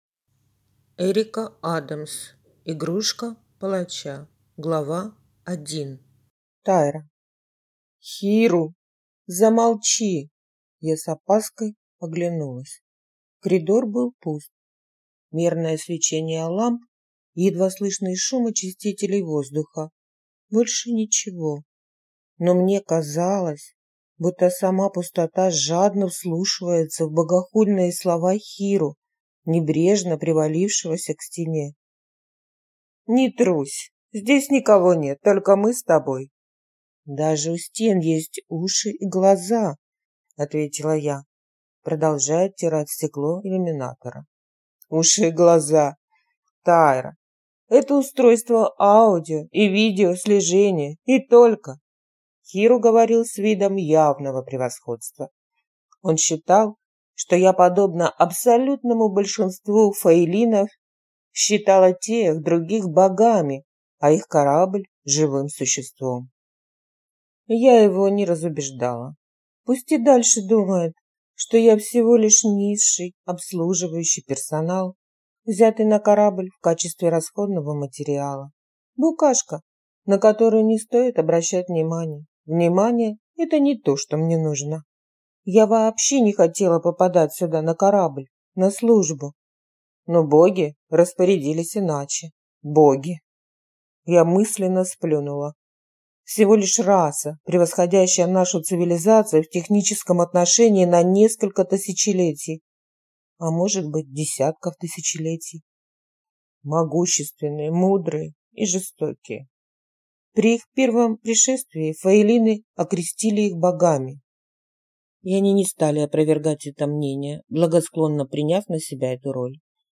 Аудиокнига Игрушка палача | Библиотека аудиокниг